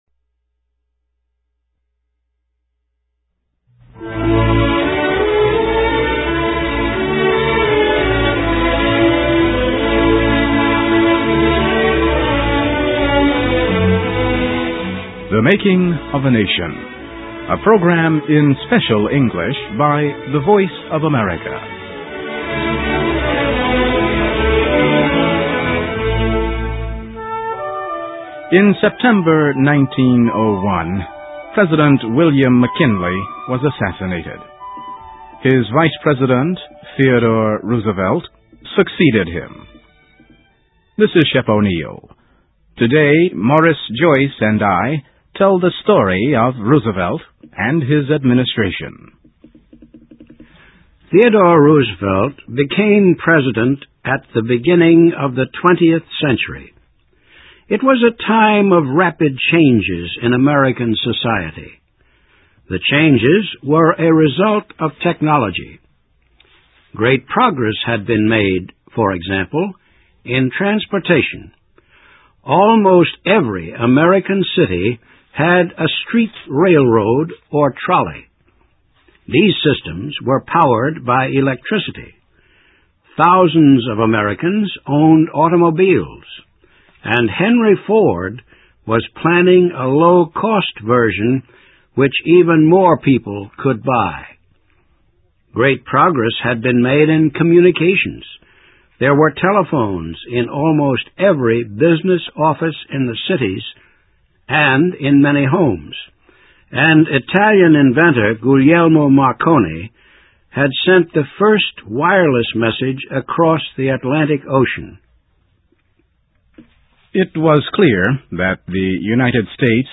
THE MAKING OF A NATION – a program in Special English by the Voice of America.